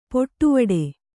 ♪ poṭṭuvaḍe